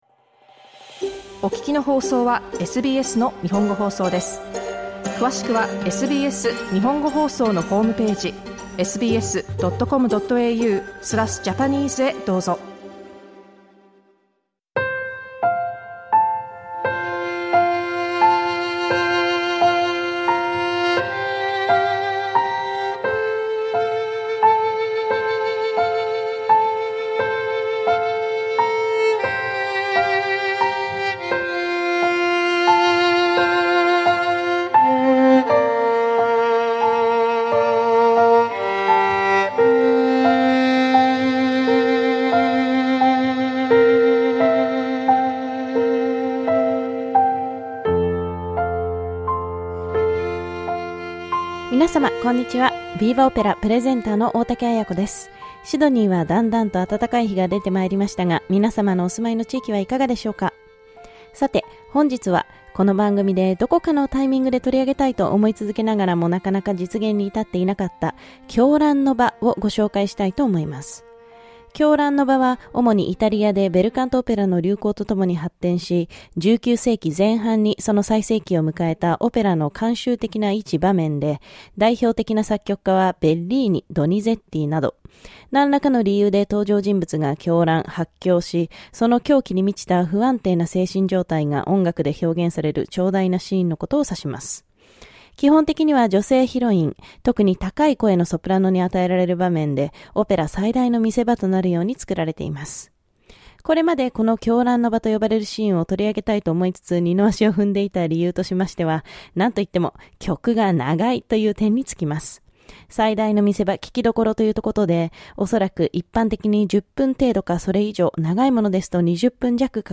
Il soprano Jessica Pratt.